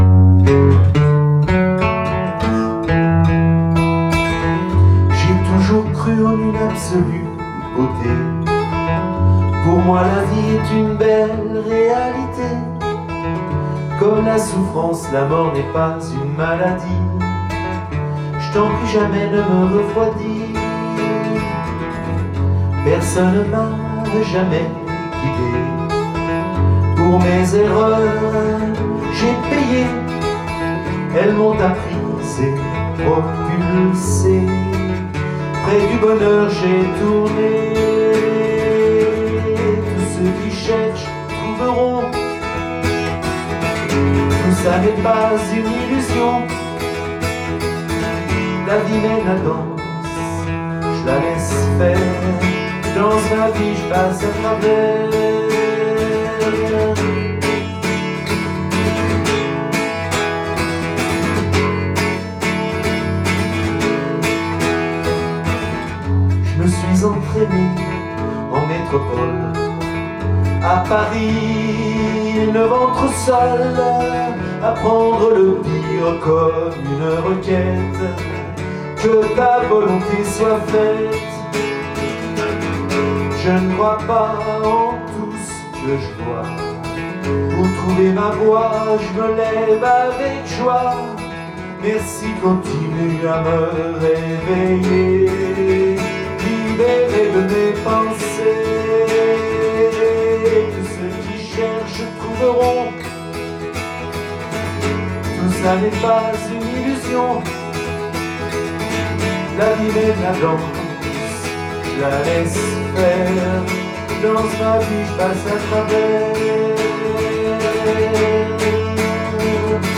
L’onde du rythme : connexion à la vie en samba.